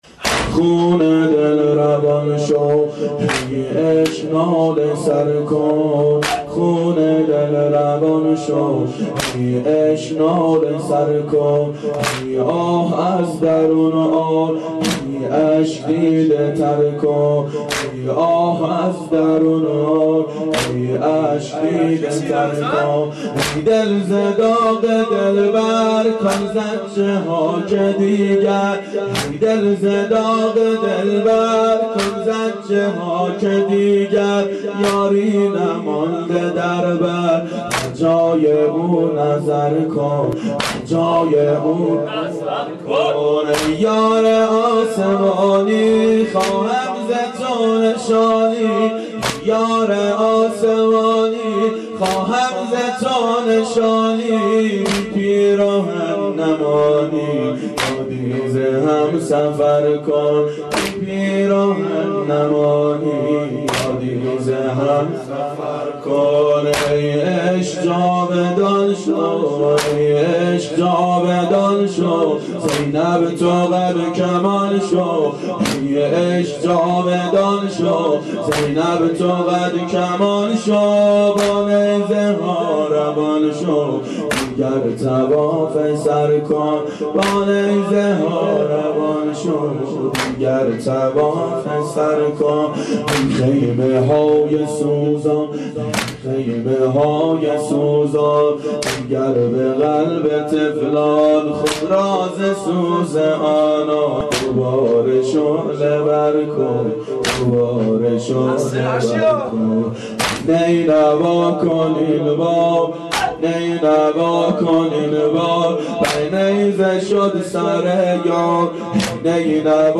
شب عاشورا 1389 هیئت عاشقان اباالفضل علیه السلام